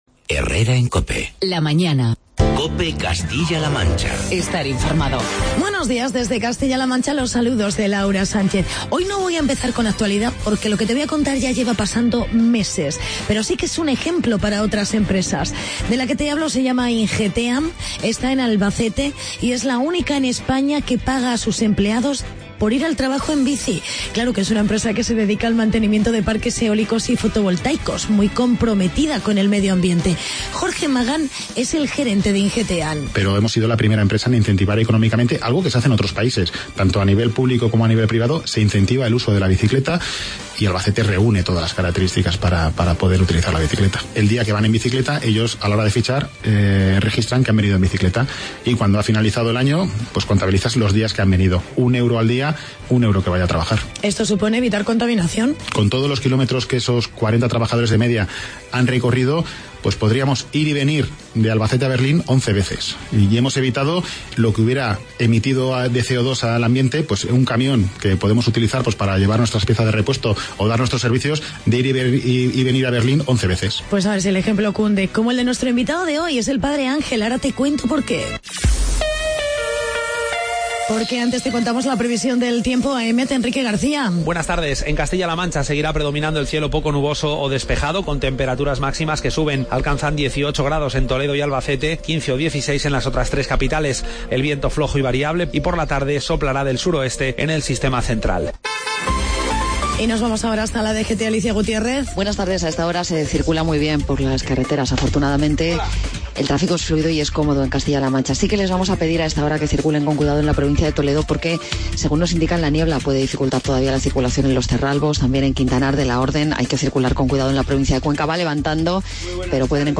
Actualidad y entrevista con el Padre Ángel, fundador de "Mensajeros de la Paz" por el nuevo restaurante Robin Hood en Toledo.